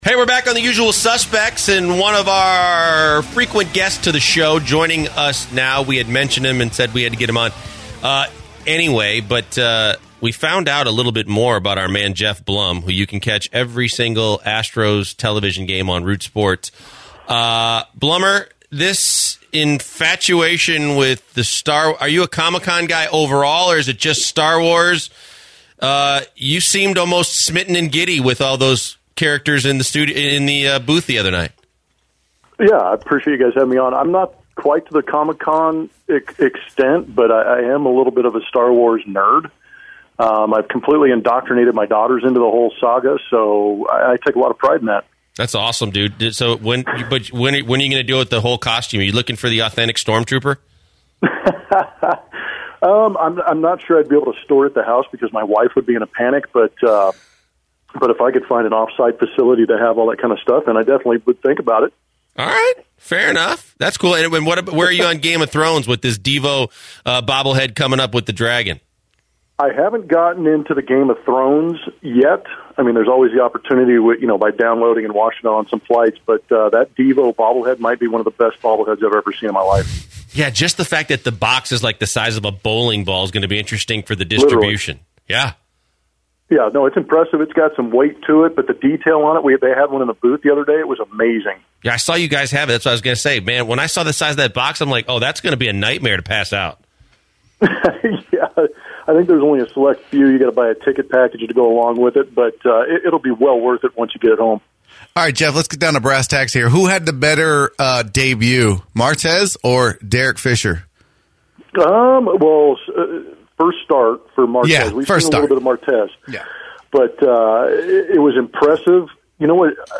Geoff Blum interview